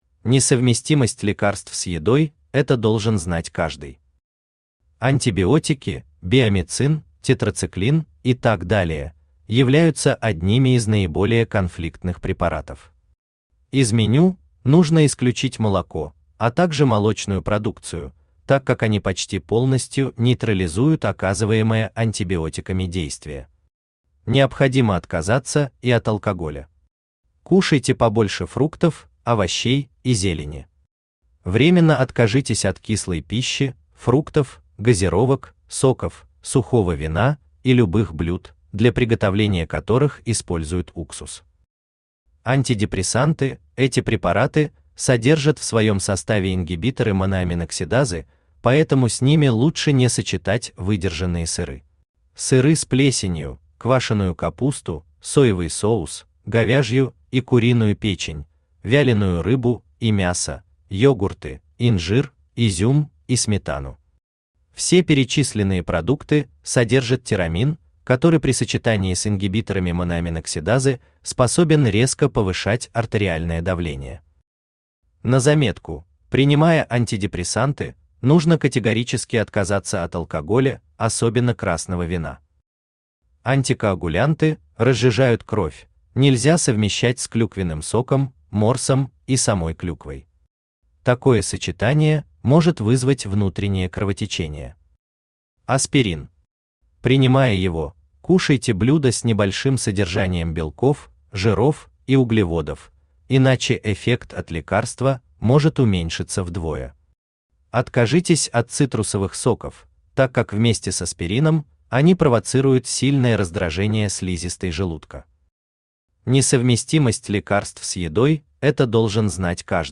Серия «Приемный покой» Автор Геннадий Анатольевич Бурлаков Читает аудиокнигу Авточтец ЛитРес.